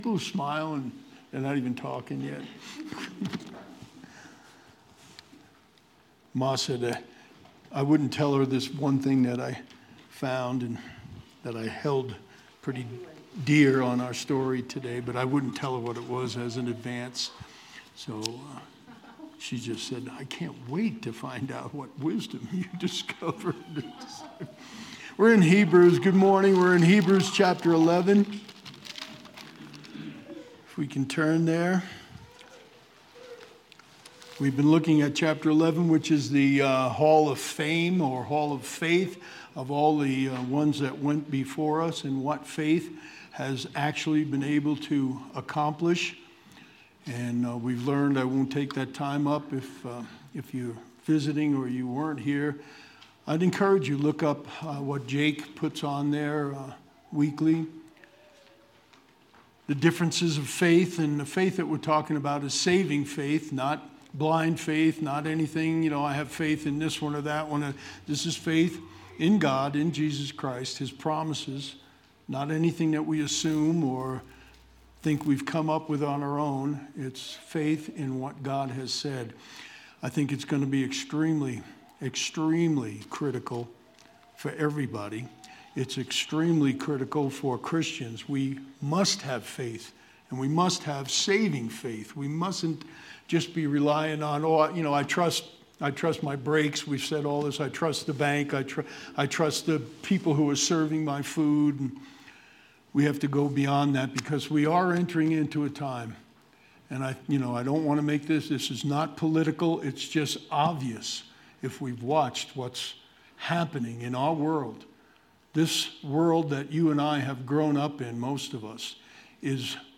February 26th, 2023 Sermon